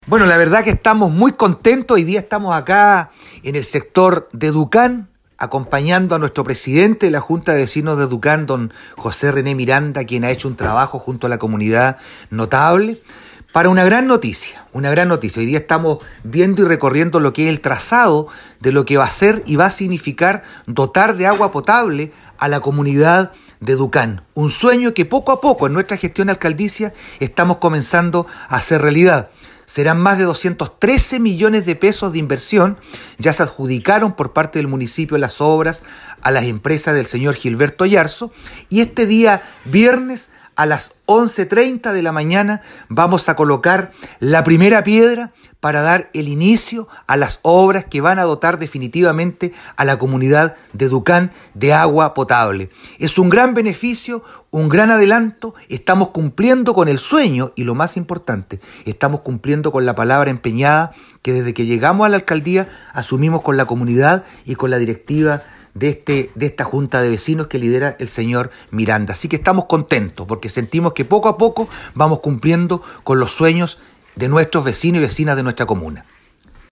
Según explicó el propio el alcalde Juan Vera, se trata de una inversión de 213 millones de pesos.
ALCALDE-VERA-DUCAN.mp3